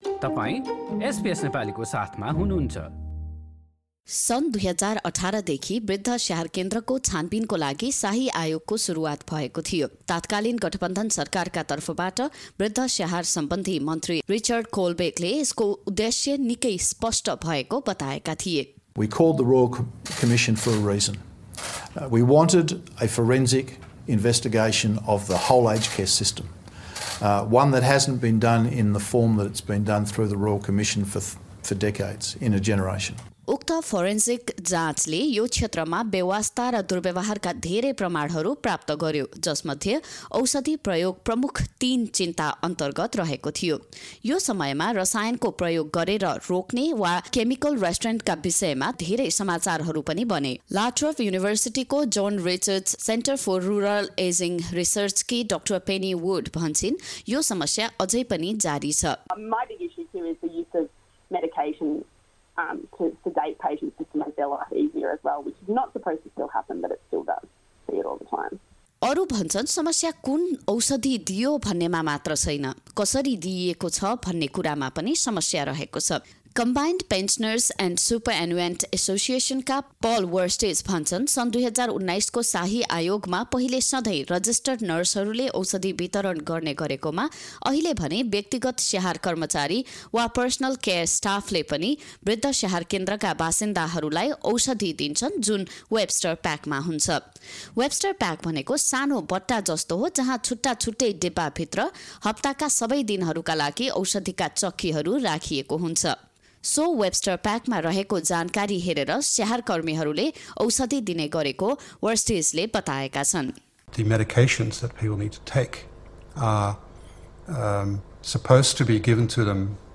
पुरा रिपोर्ट सुन्नुहोस्: null हाम्रा थप अडियो प्रस्तुतिहरू पोडकास्टका रूपमा उपलब्ध छन्।